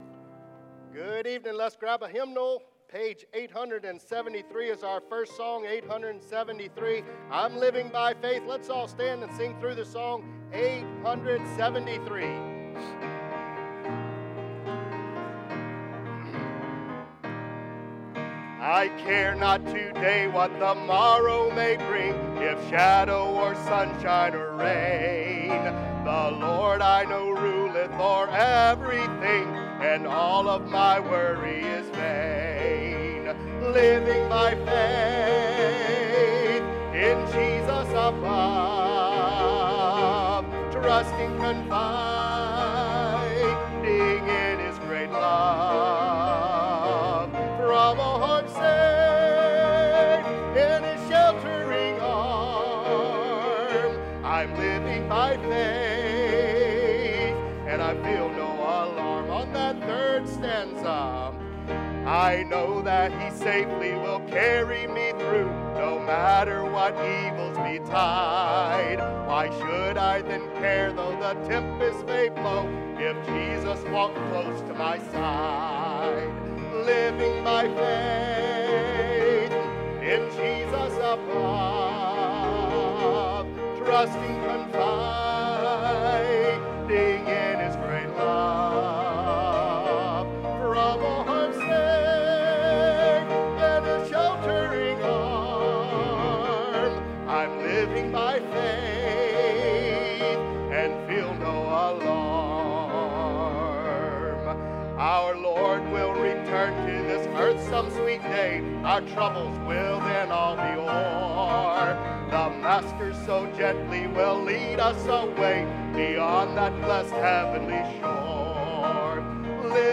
Sermons | Tri-City Baptist Church